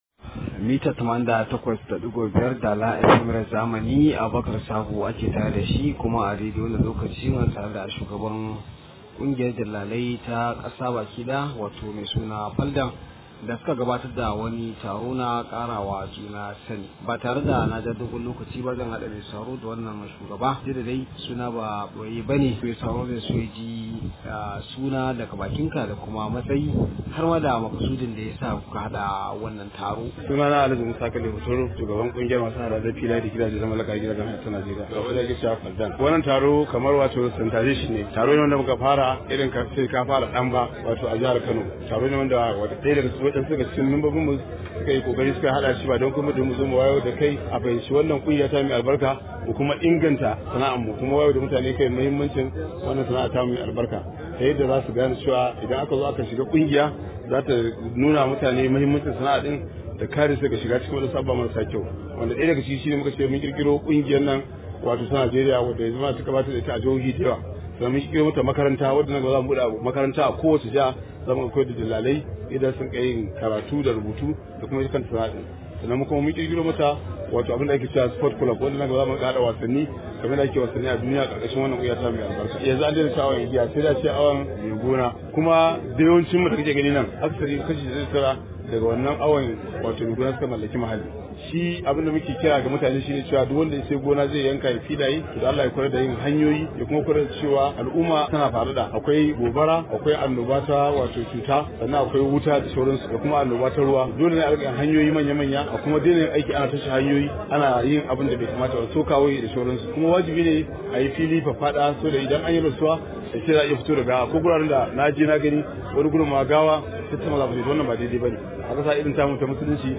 Ga cikakken rahoton da wakilin namu ya aiko mana.